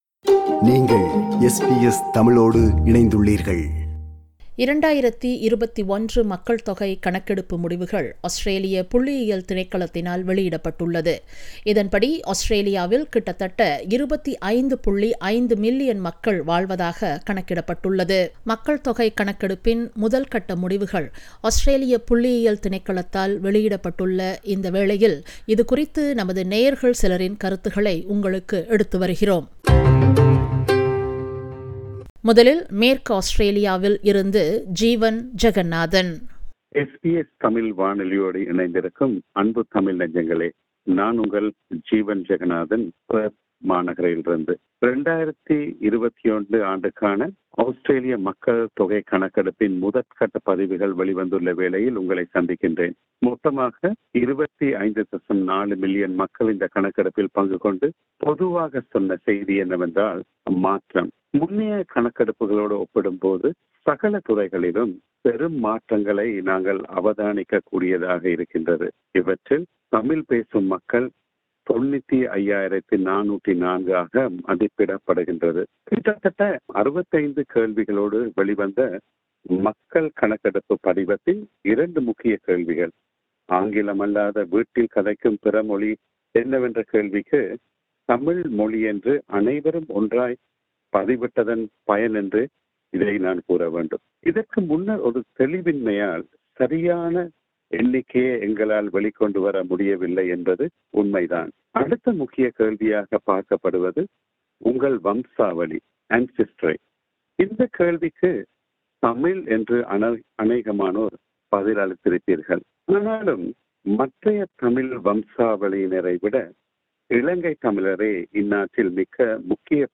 2021 Census result was released last Tuesday by Australian Bureau of Statistics (ABS). This feature brings the opinion of our listeners from all over Australia.